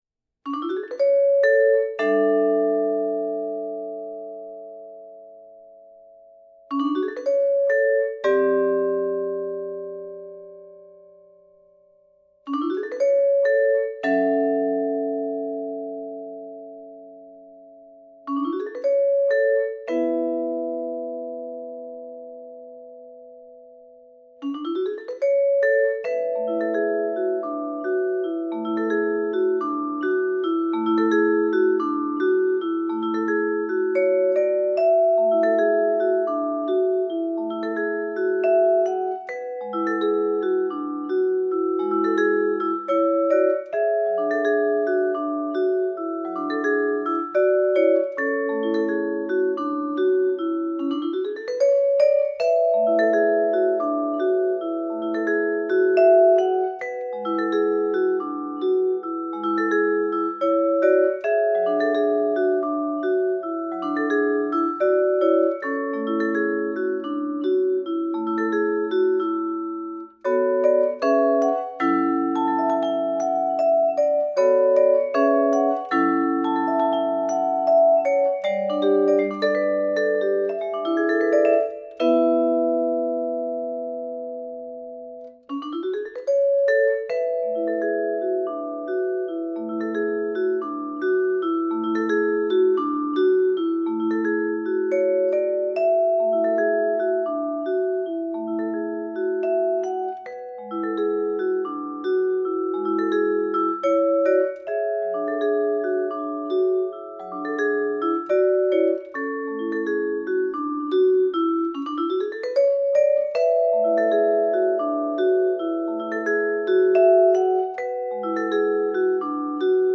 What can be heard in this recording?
Voicing: Vibraphone